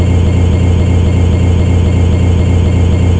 00854_Sound_househum.mp3